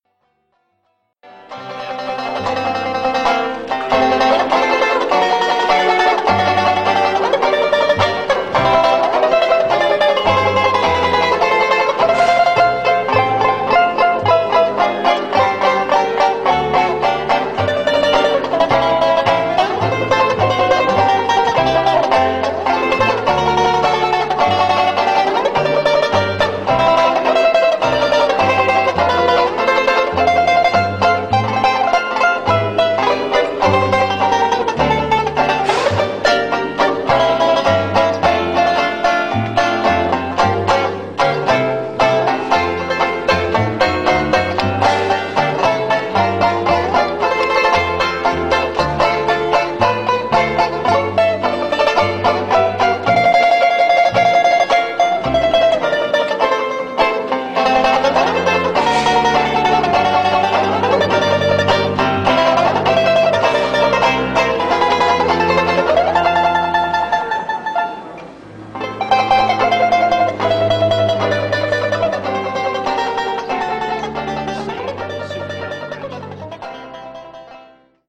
8-beat intro.